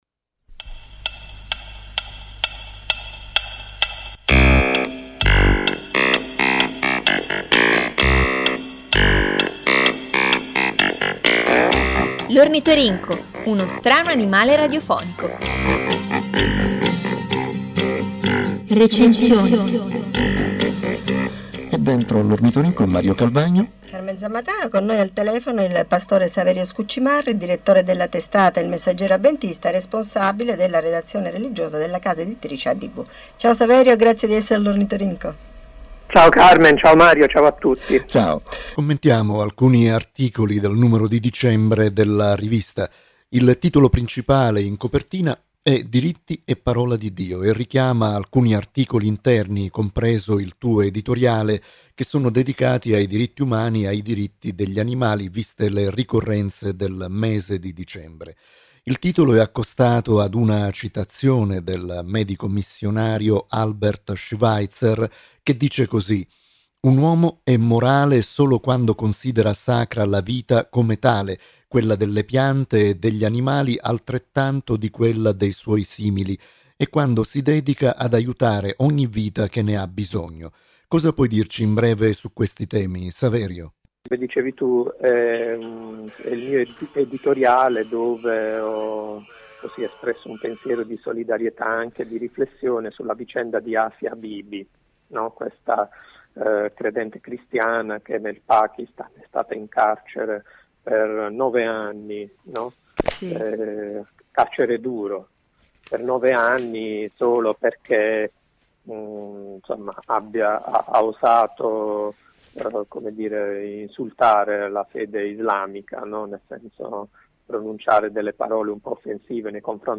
Sul numero di dicembre, Radio Voce della Speranza di Roma ha dialogato con il direttore. Ascolta l’intervista